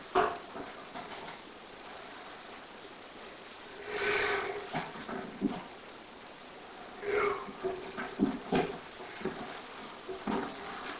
Electronic Voice Phenomena (EVP)
While investigating a private residence, we caught this creepy voice ...sounds like a deep inhale and then it says YOU!!!